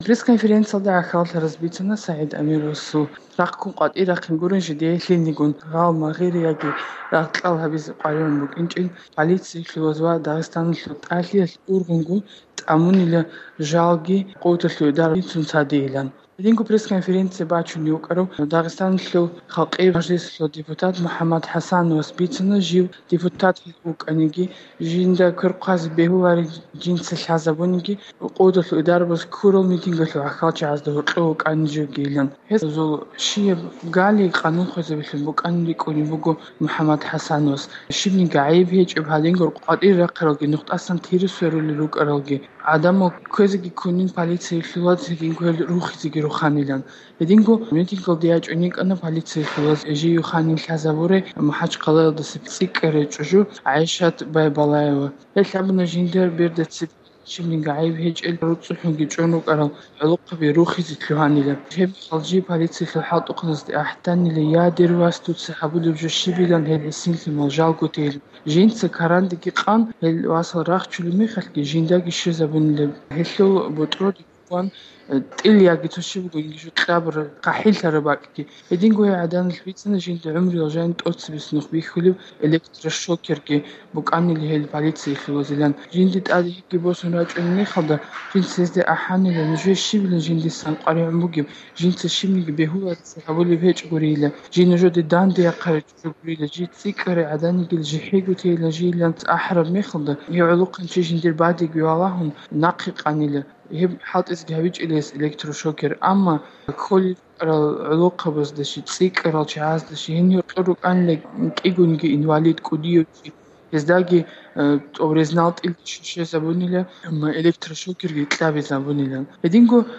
Амировасул рахъккуразул пресс-конференция